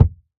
Minecraft Version Minecraft Version snapshot Latest Release | Latest Snapshot snapshot / assets / minecraft / sounds / block / packed_mud / step5.ogg Compare With Compare With Latest Release | Latest Snapshot
step5.ogg